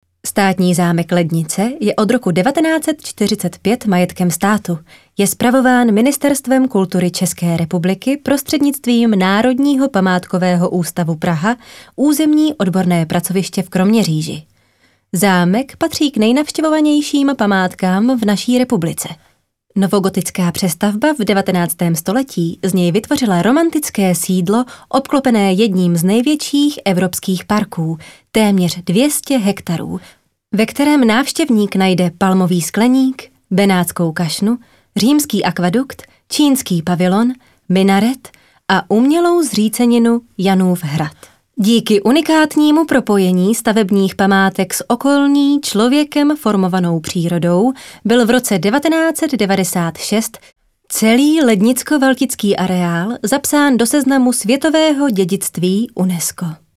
ukázka voice over
ukazka-voice-over.mp3